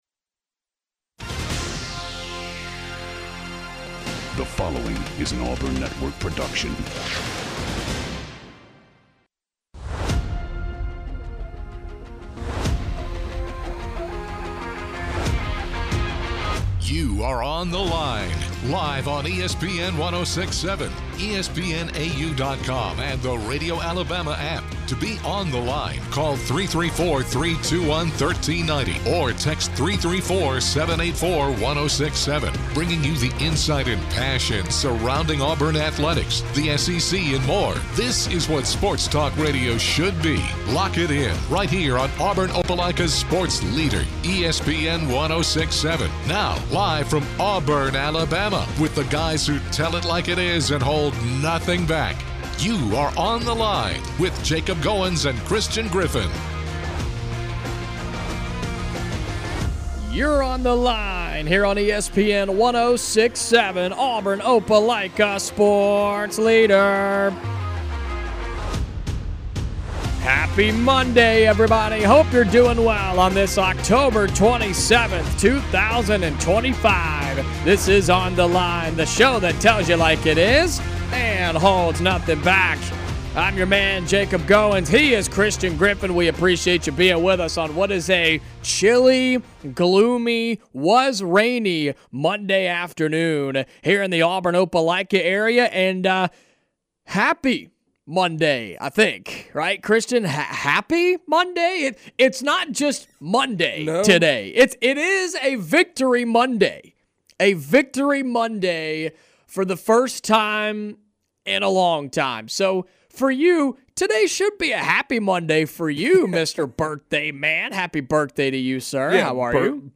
The guys get opinions from dozens of callers and texters, giving their thoughts on the win.